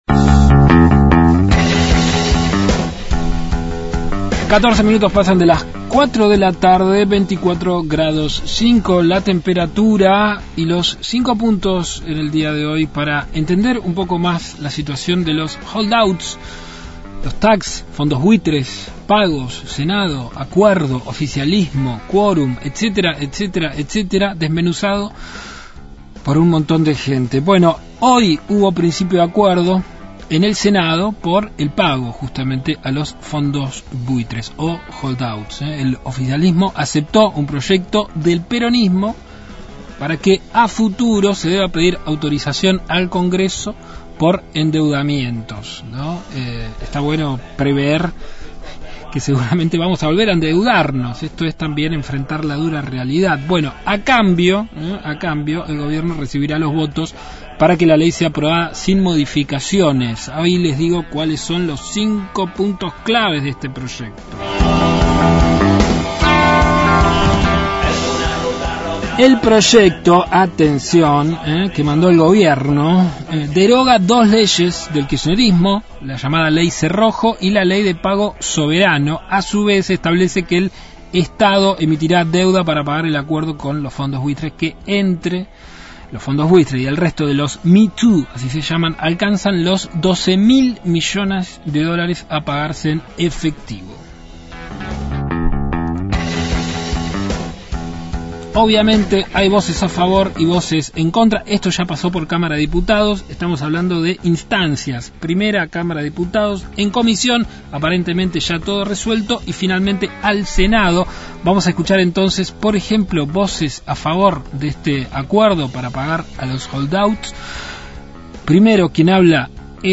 Un montón de gente/ Informe sobre el acuerdo con los holdouts – Radio Universidad